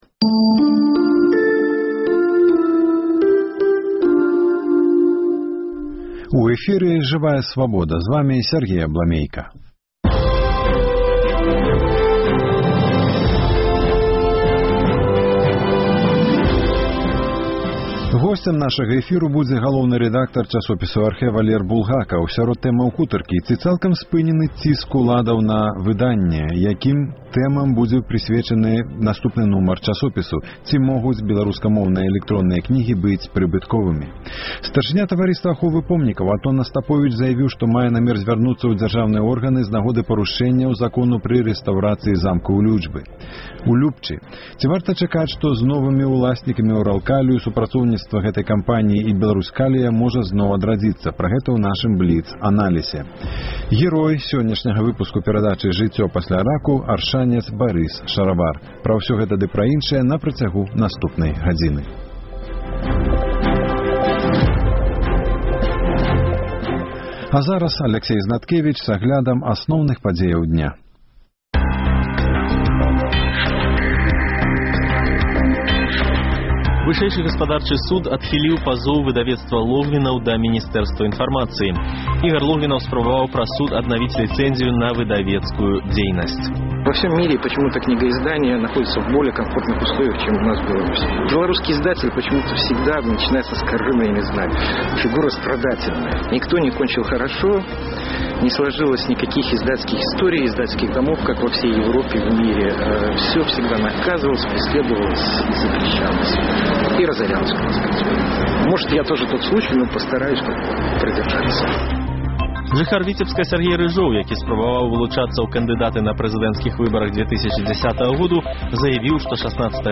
Апытаньне ў Горадні. Як вы ставіцеся да таго, што ў Беларусі вернутая практыка прымусовага лячэньня ад алькагалізму?